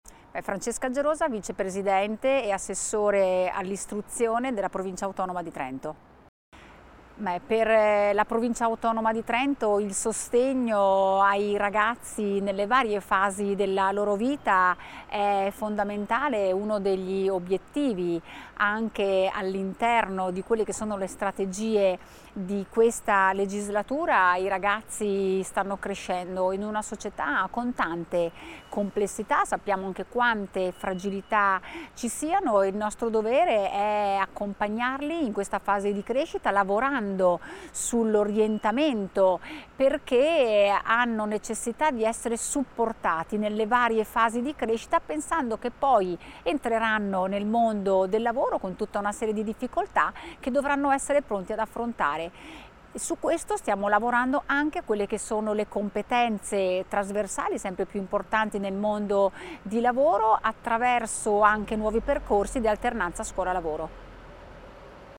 Vicepresidente Francesca Gerosa
Intv Francesca Gerosa IT.mp3